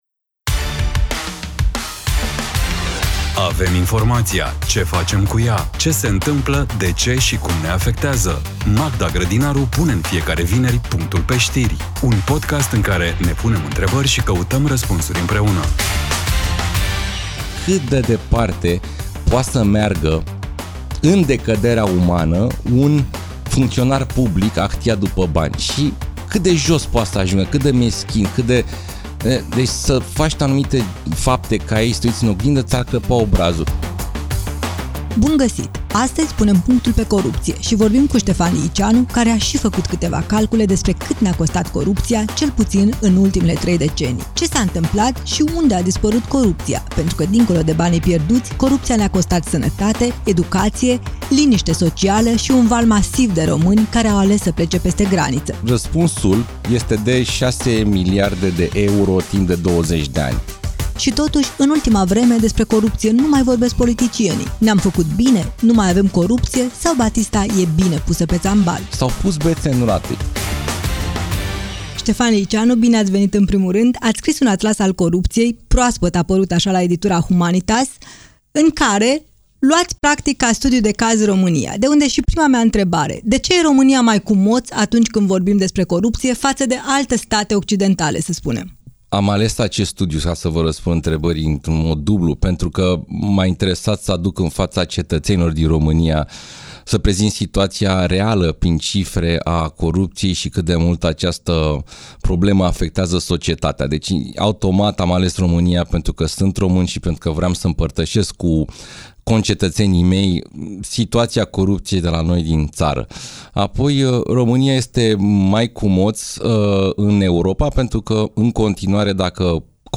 Un dialog inteligent, relaxat și necesar.